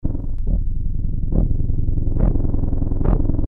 Tag: 130 bpm Dance Loops Drum Loops 319.86 KB wav Key : Unknown